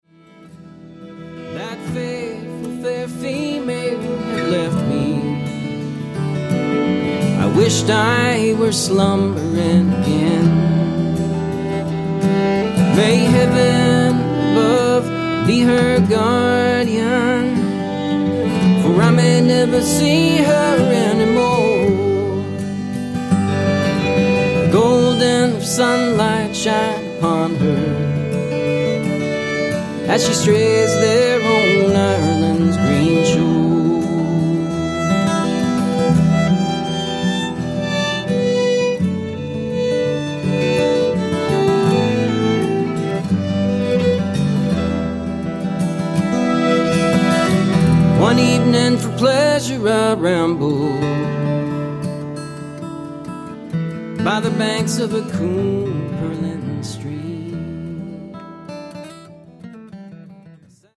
ballad
fiddle & guitar duo
Scots-Irish & Americana